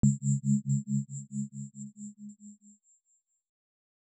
tone2.R.wav